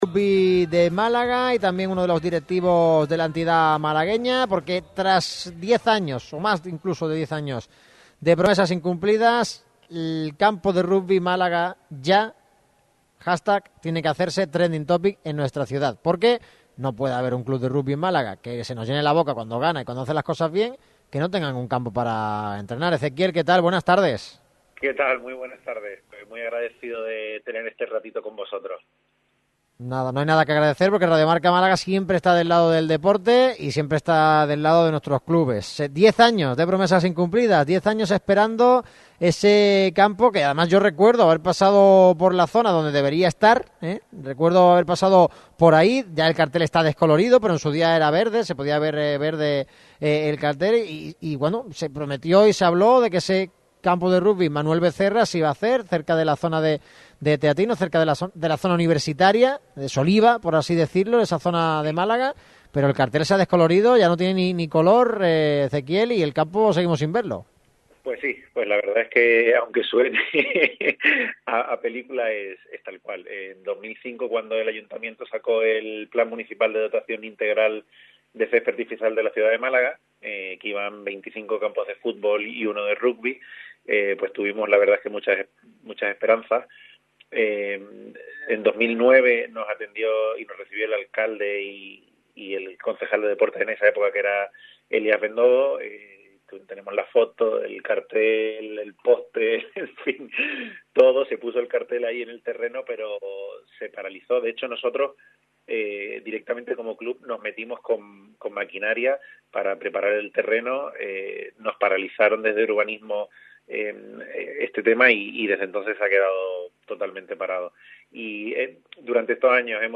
Además de su intervención radiofónica, el club emitió el siguiente comunicado para dar difusión a las carencias infraestructurales que padecen: